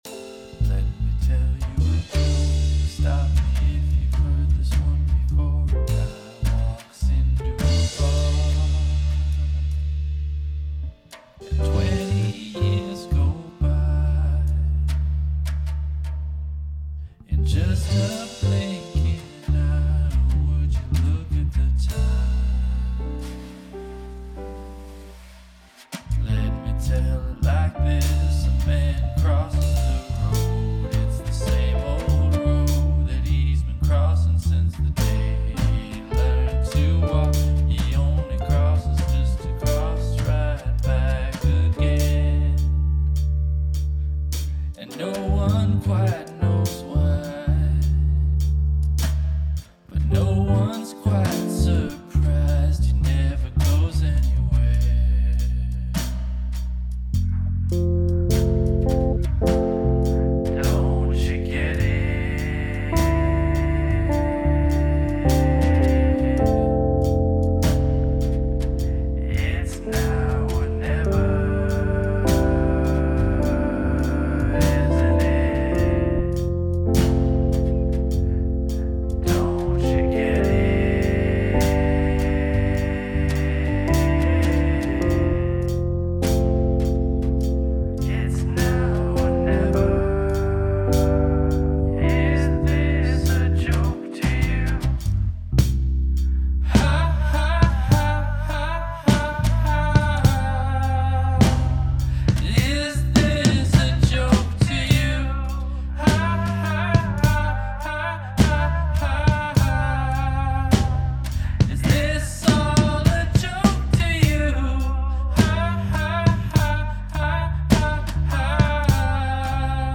The bass following the voice is fun.